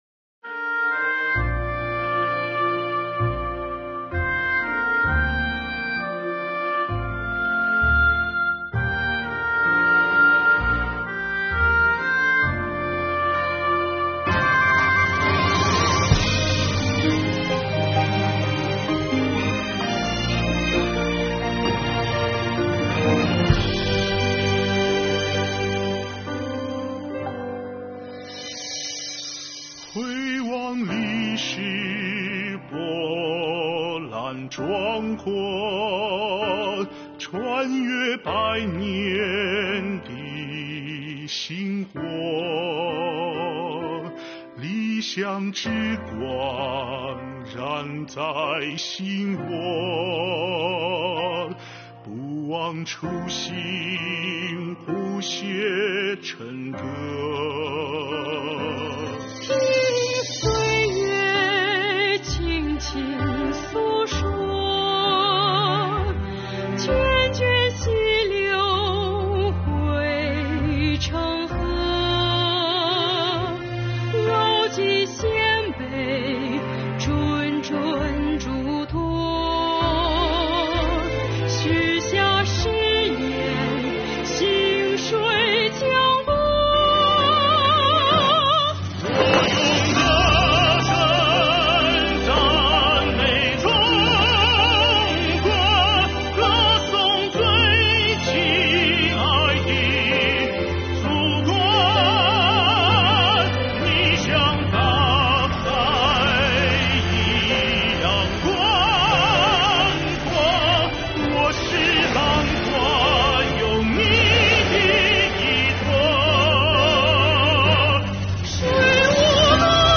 这首歌曲采取了抒情和激情相结合的笔调，将优美动人的旋律与朴实真挚的歌词结合起来，表达了税务干部及广大纳税人缴费人对我们伟大的党的真诚歌颂和衷心祝愿。